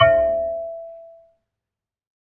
kalimba2_wood-E4-pp.wav